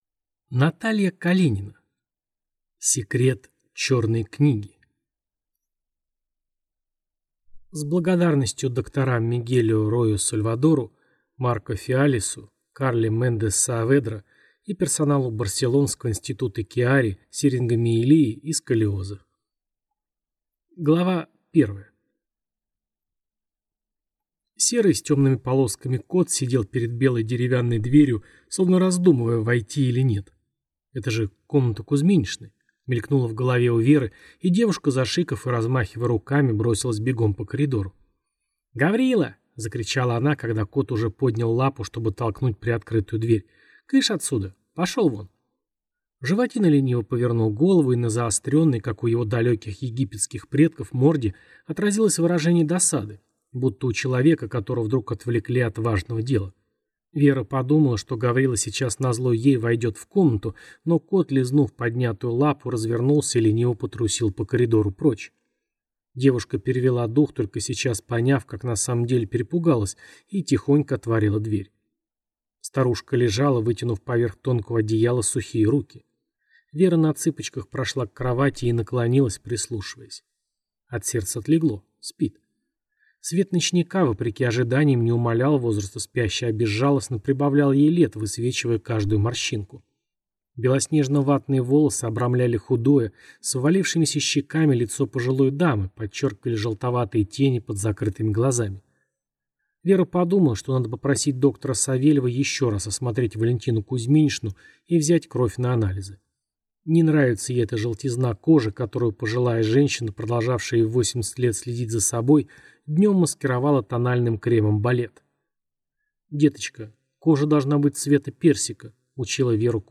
Аудиокнига Секрет черной книги | Библиотека аудиокниг